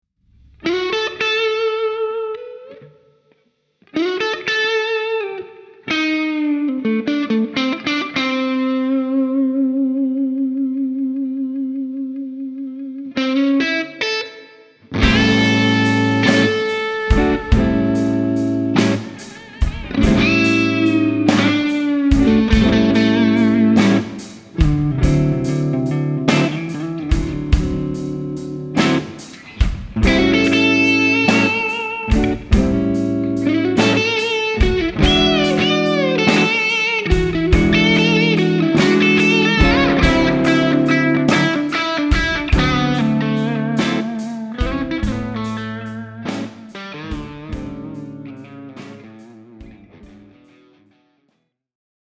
Il Stormy Monday è un humbucker dolce e articolato con bassi caldi, medi vuoti e una fascia alta liscia, estremamente chiara e dinamica.
Il Stormy Monday riproduce fedelmente i toni dei PAF più luminosi del '57 e '58 con un sustain naturale morbido, meravigliose dinamiche di pick e separazione delle corde. Le note basse hanno calore e pienezza; gli alti sono dolci e morbidi mentre i medi sono leggermente scavati producendo un tono vintage morbido e percussivo.